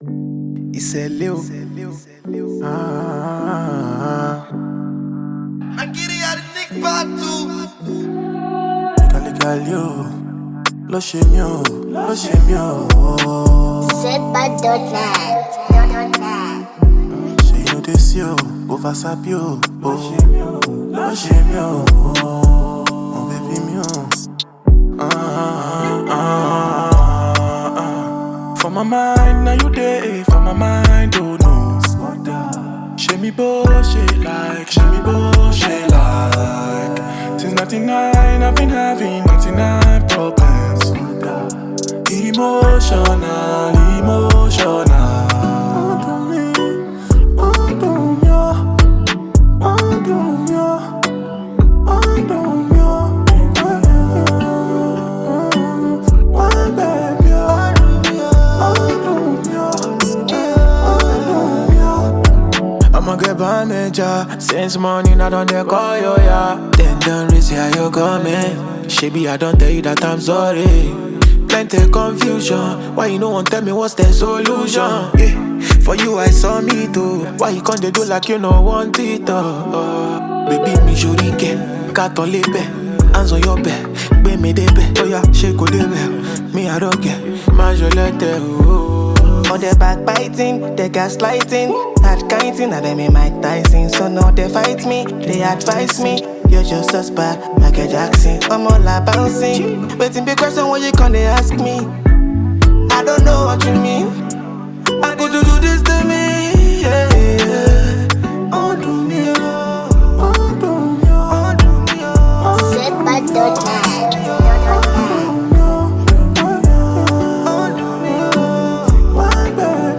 Category: Naija Music